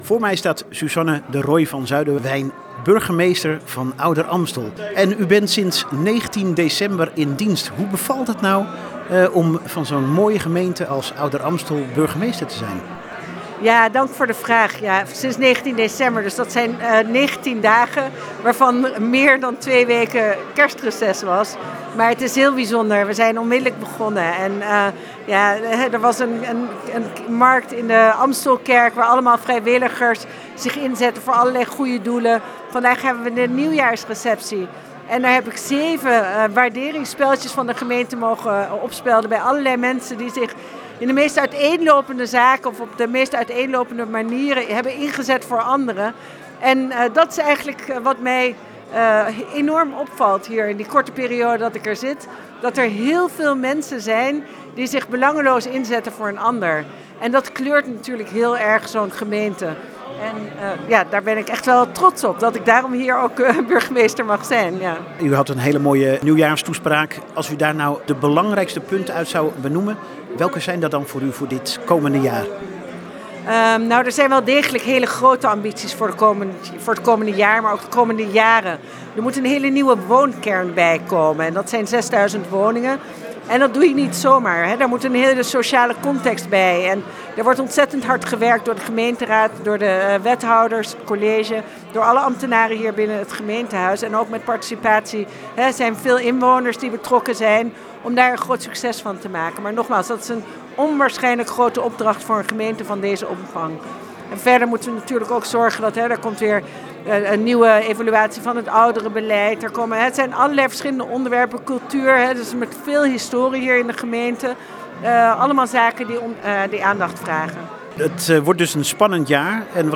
Wij spraken op de nieuwjaarsreceptie met de kersverse burgemeester, sinds 19 december 2024 in functie, sprak over haar eerste indrukken van Ouder-Amstel en haar plannen voor de toekomst.